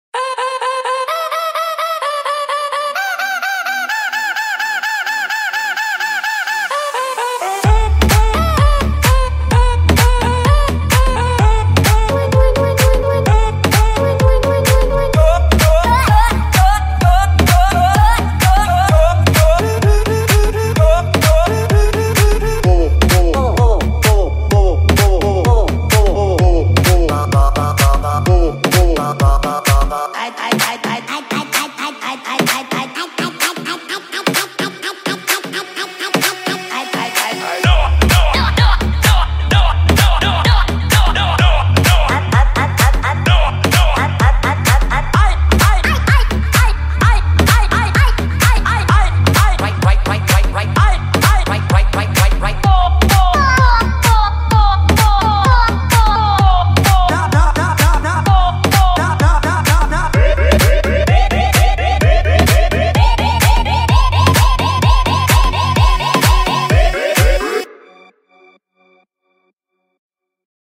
(COVER)